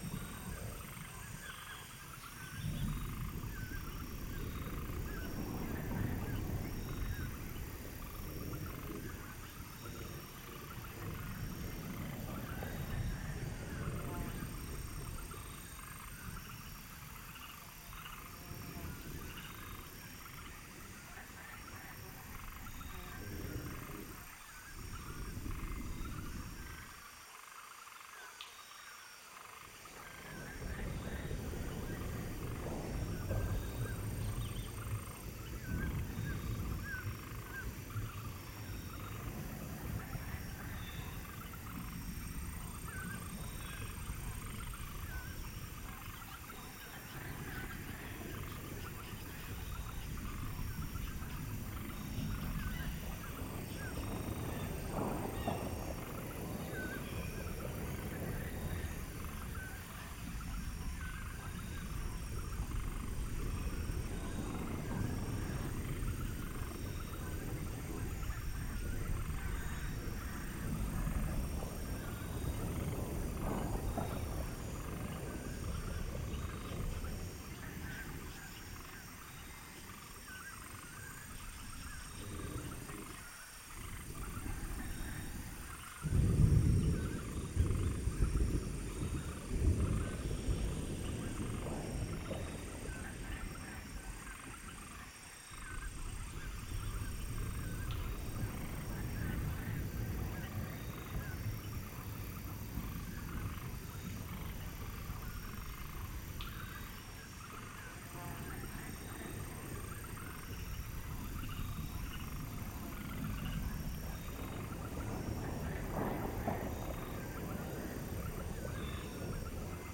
Thunderous Swamp.ogg